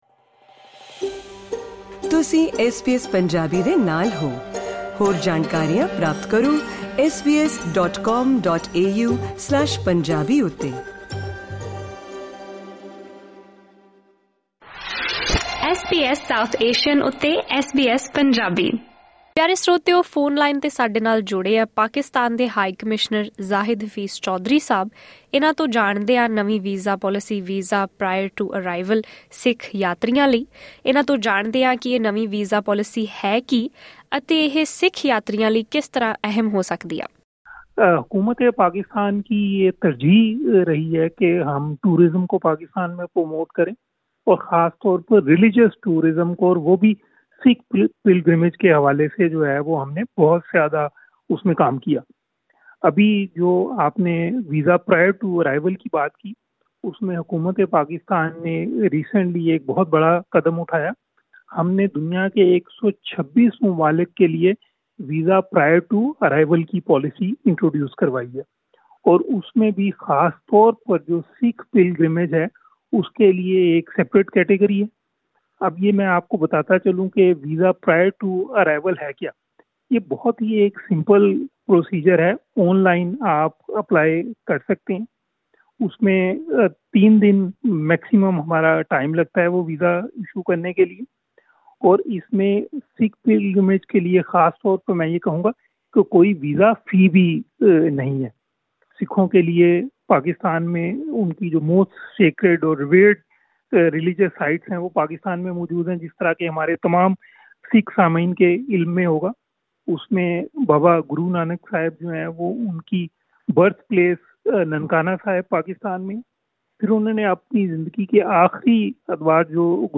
ਇੰਟਰਵਿਊ